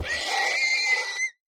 mob / horse / death.ogg
death.ogg